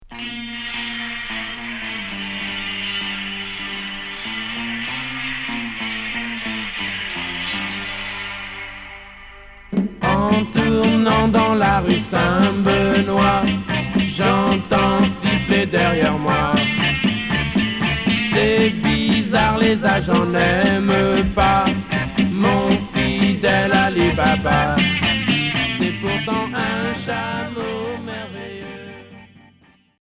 Rock garage 60's